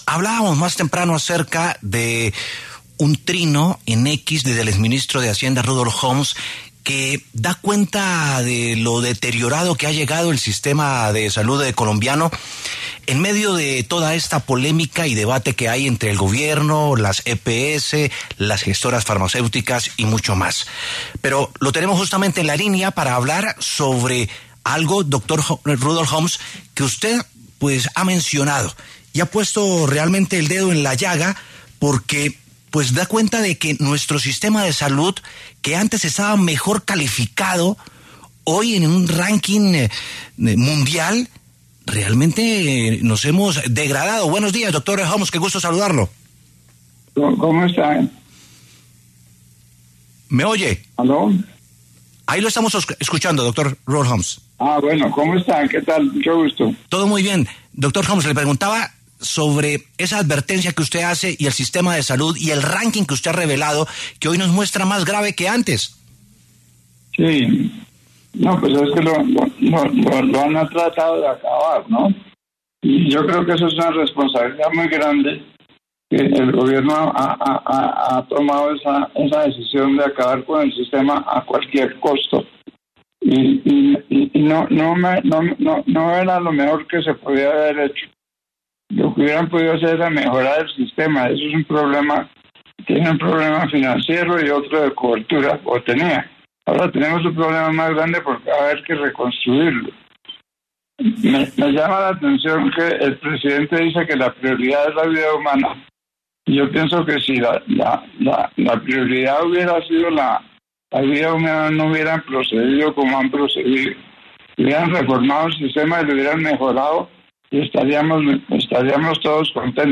En diálogo con La W, el exministro Rudolf Hommes conversó acerca de lo que ha considerado un deterioro en el sistema de salud colombiano en medio de toda la polémica entre el Gobierno, las EPS, las gestoras farmacéuticas y más.